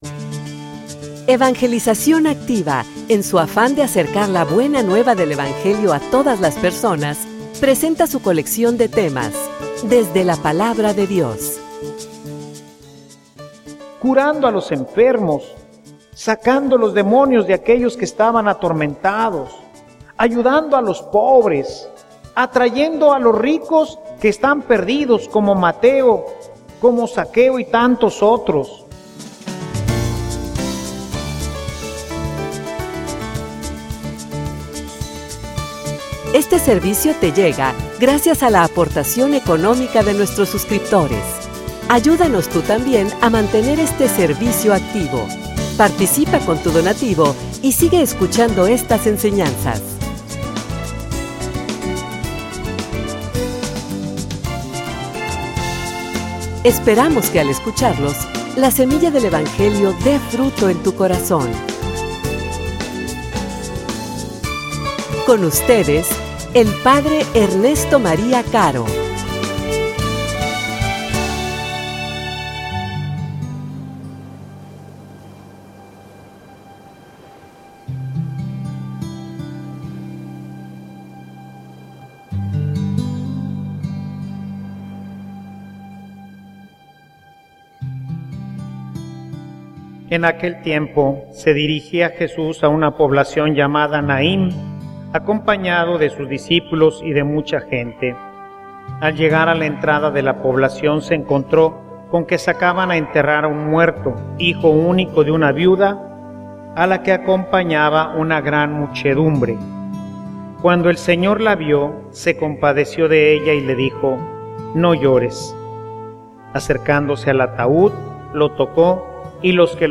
homilia_Profetas_de_nuestros_tiempos.mp3